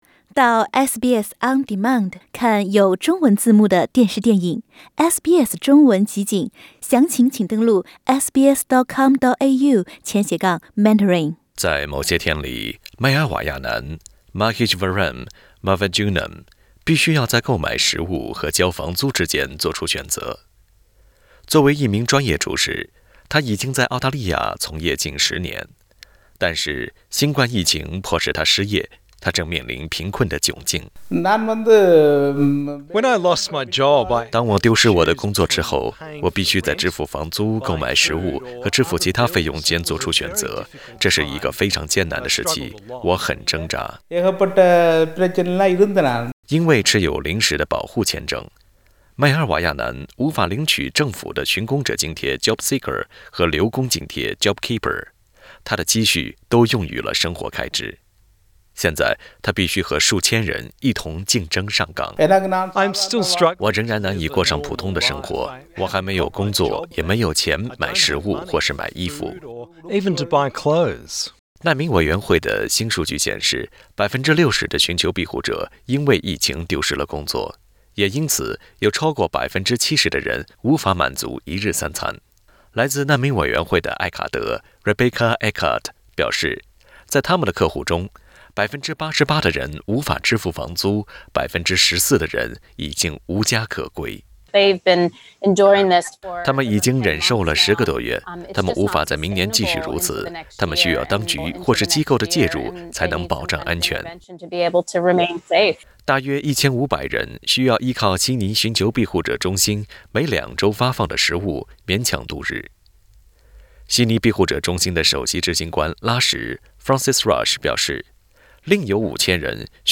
虽然澳大利亚的经济已经显露出复苏的迹象，但是一个援助组织的最新数据显示，数万人已迫于生活的压力，仅仅依托紧急救援物资勉强度日，社区里的孩子们也显示出营养不良的迹象。（欢迎点击图片，收听采访）